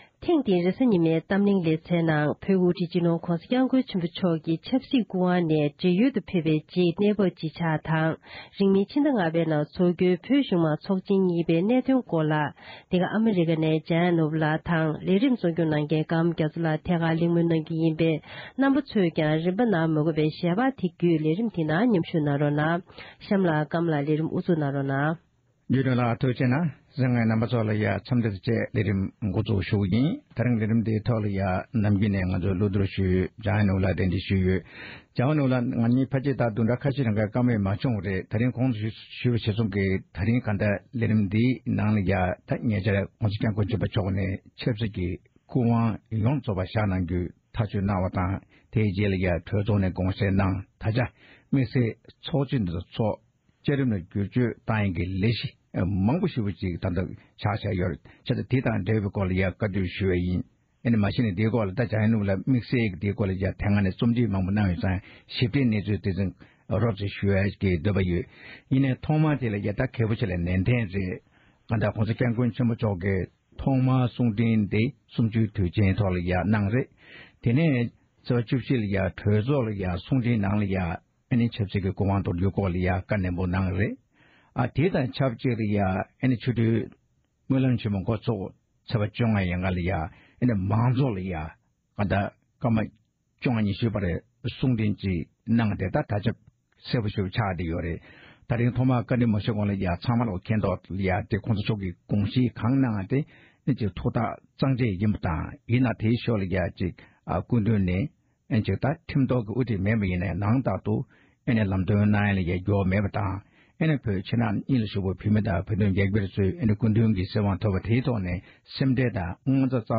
ཐེངས་འདིའི་རེས་གཟའ་ཉི་མའི་གཏམ་གླེང་གི་ལེ་ཚན་གྱི་ནང་དུ་སྤྱི་ནོར་༸གོང་ས་༸སྐྱབས་མགོན་ཆེན་པོ་མཆོག་གིས་ཆབ་སྲིད་ཀྱི་སྐུ་དབང་དོར་གནང་བའི་རྗེས་སུ་རྒྱལ་ཁབ་ཀྱི་དབུ་ཁྲིད་ཀྱི་མཚན་གནས་བཞེས་ཕྱོགས་དང་།